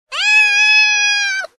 Animal Sounds / Cat Meow Sound / Sound Effects
Animal Sounds / Cat Meow Sound / Sound Effects 13 Jan, 2023 Cat Loud Meow Sound Effect Read more & Download...
Cat-loud-meows-sound-effect.mp3